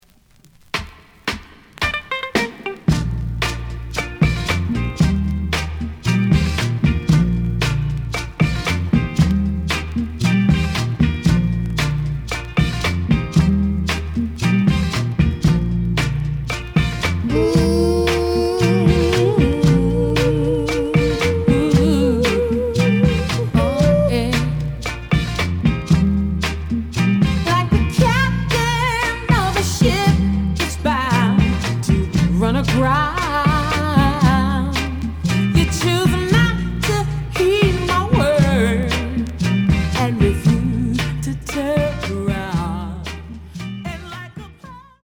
The audio sample is recorded from the actual item.
●Genre: 2000's ~ Soul